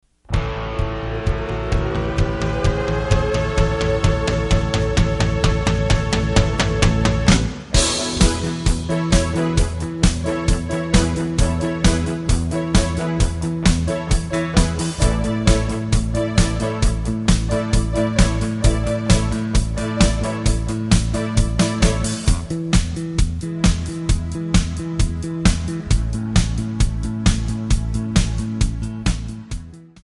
Backing track Karaoke
Pop, Disco, 1980s